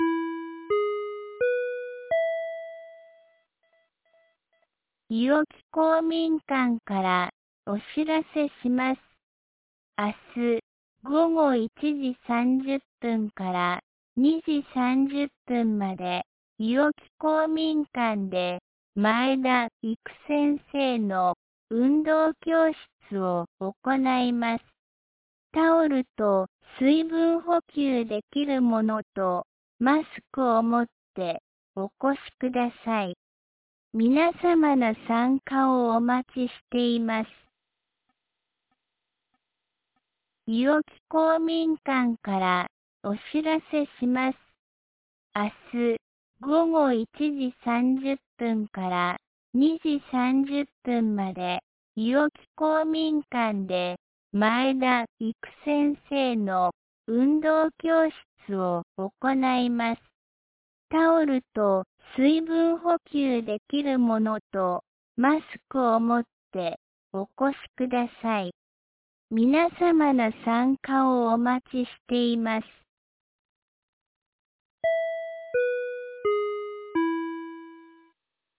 2025年08月25日 17時11分に、安芸市より伊尾木へ放送がありました。